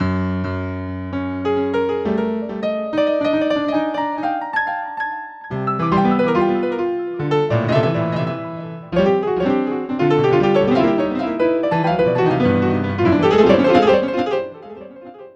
Track 07 - Piano 01.wav